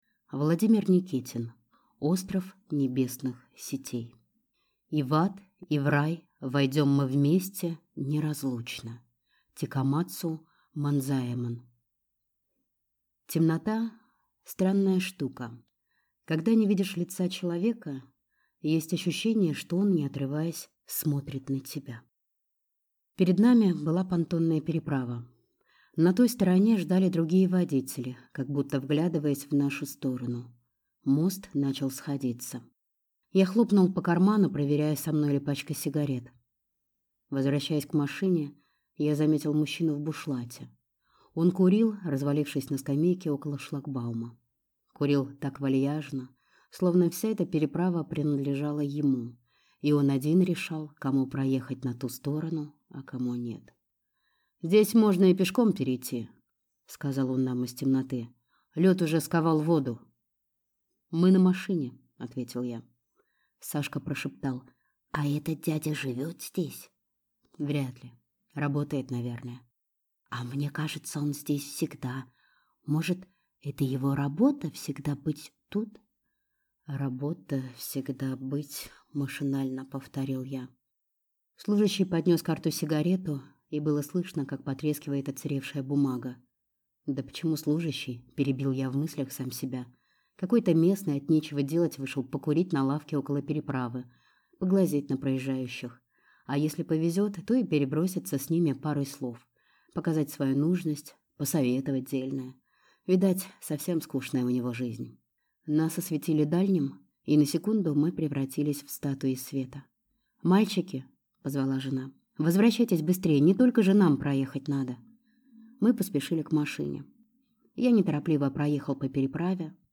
Аудиокнига Остров Небесных сетей | Библиотека аудиокниг